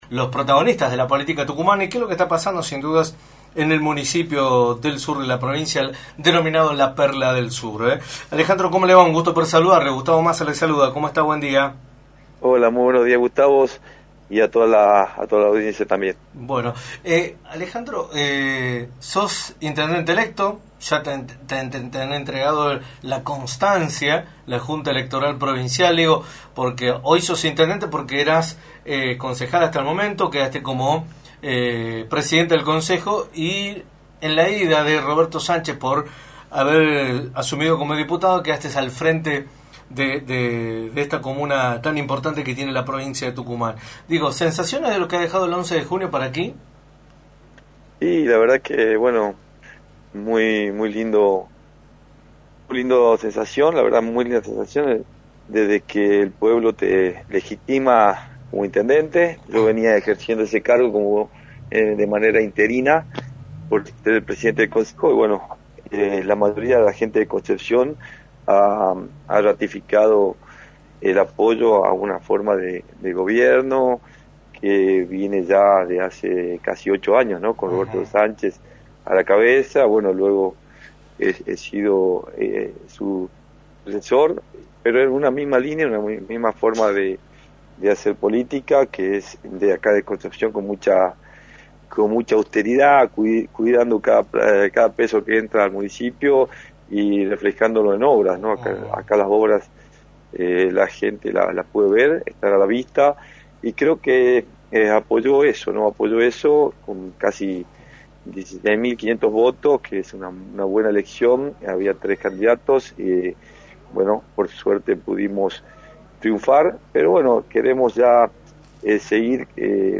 Alejandro Molinuevo, Intendente electo de Concepción, analizó en Radio del Plata Tucumán, por la 93.9, los resultados de las elecciones provinciales y el escenario político de Tucumán.